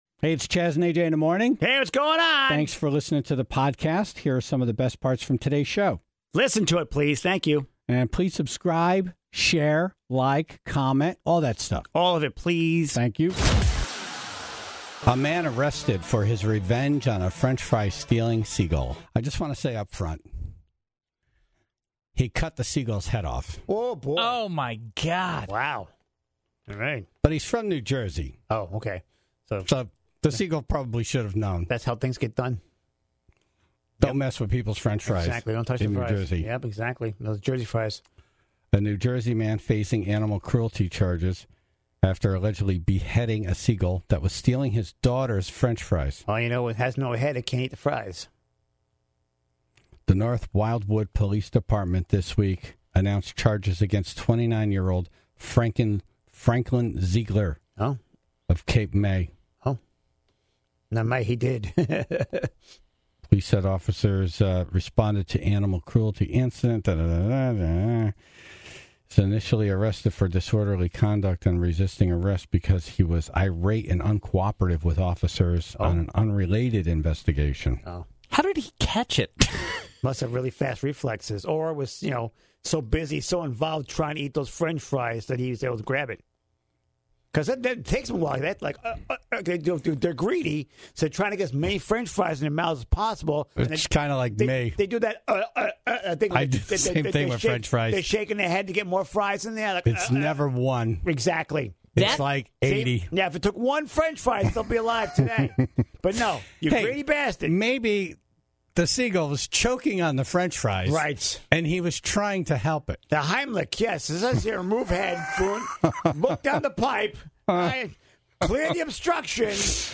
asked the Tribe to call in their stories, which included a police officer's tale of surviving an attack from a hammer-swinging suspect.